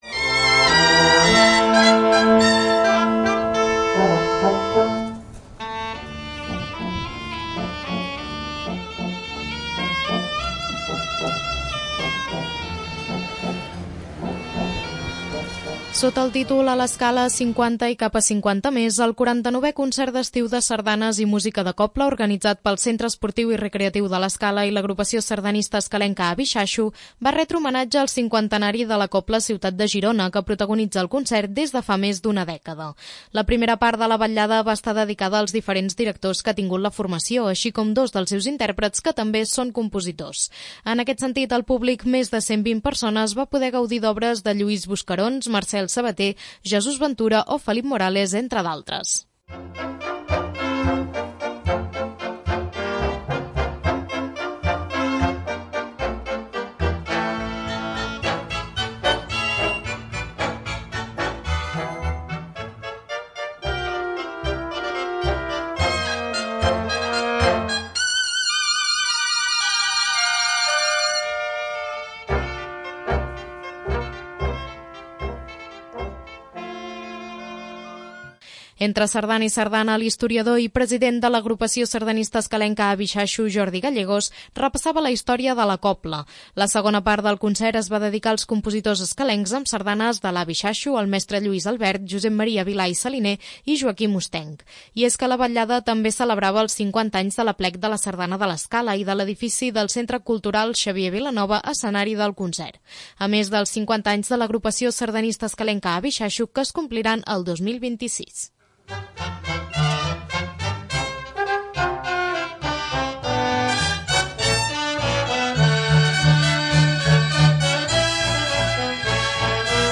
2. L'Informatiu
El 49è Concert d'Estiu de Sardanes de l'Escala repassa la història de la Cobla Ciutat de Girona